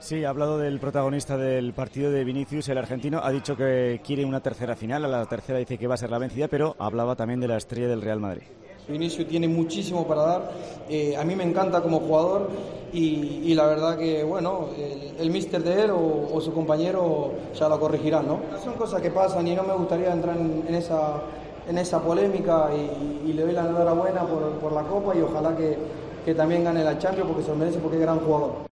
El delantero de Osasuna, Chimy Ávila, valoró en zona mixta que su equipo ha crecido esta temporada y dijo sobre Vinicius Jr. que es un jugador muy desequilibrante.